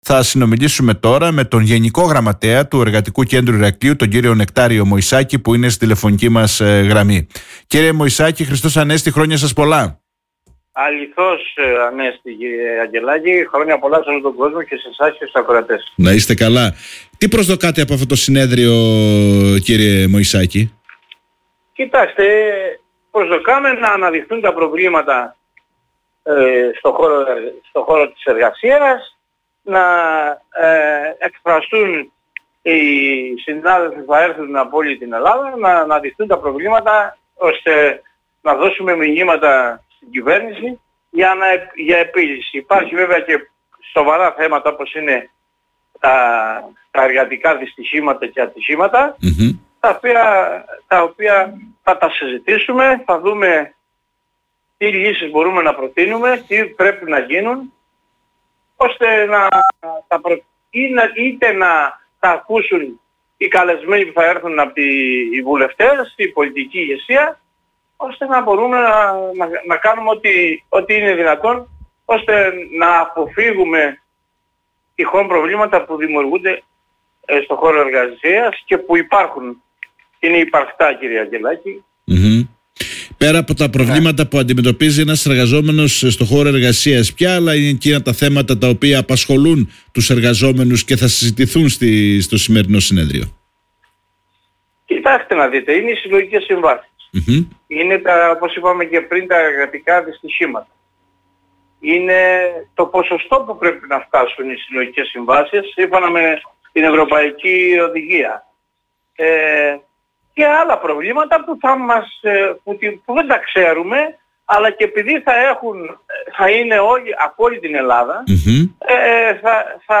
μίλησε στην εκπομπή “Όμορφη Μέρα”